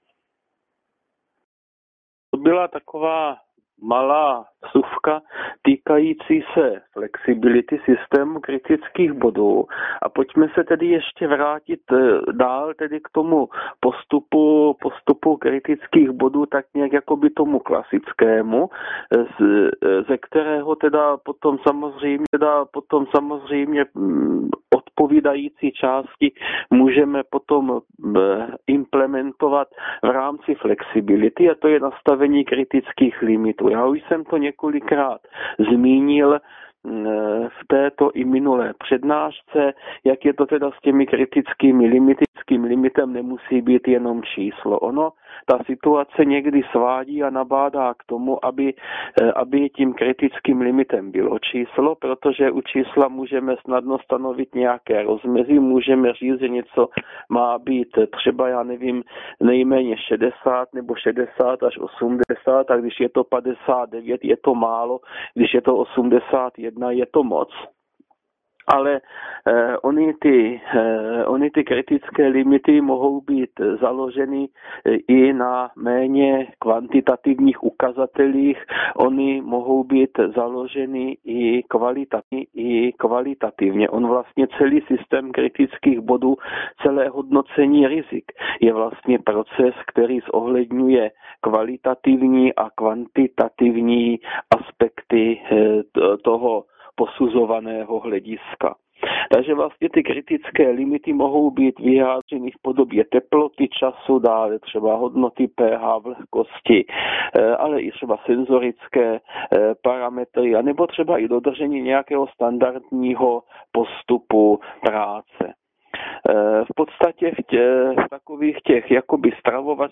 LF:MNHV0721p Hygiena výživy I - přednáška